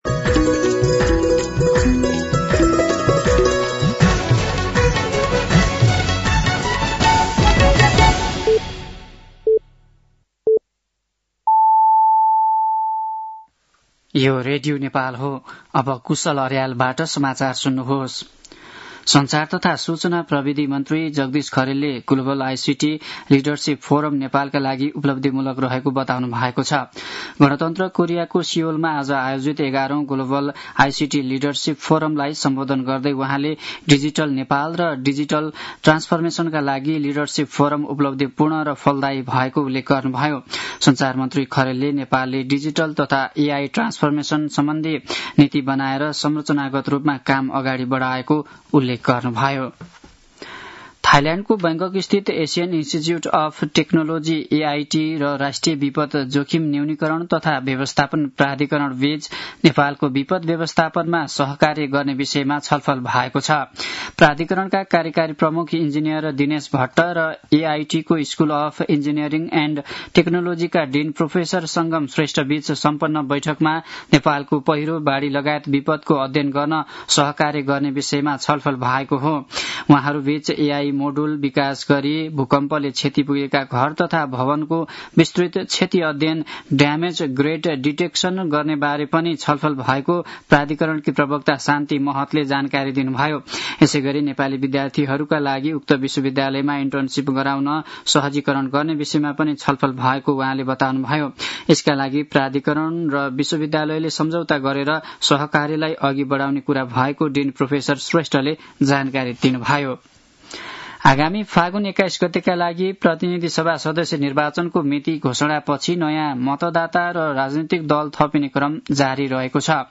साँझ ५ बजेको नेपाली समाचार : २६ कार्तिक , २०८२
5-pm-news-7-26.mp3